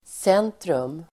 Uttal: [s'en:trum]
centrum.mp3